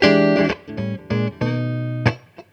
JAZZY.wav